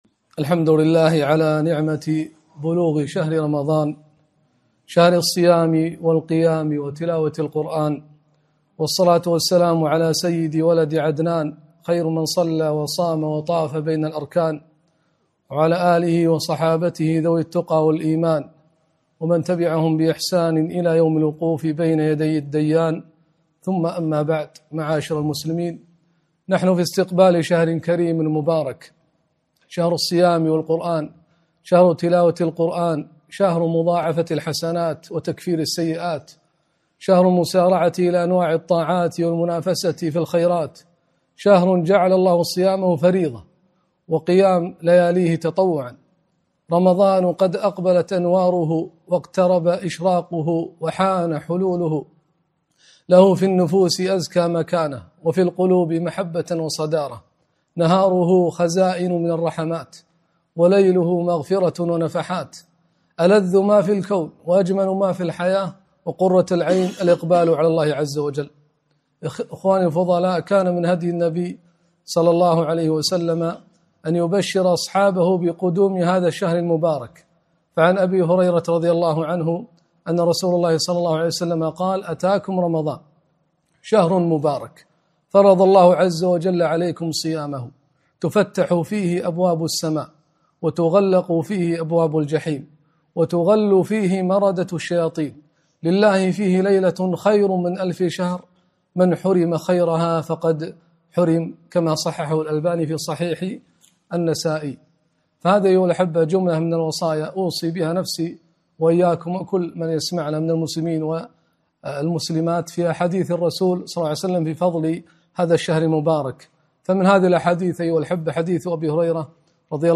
كلمة - الفرح بقدوم رمضان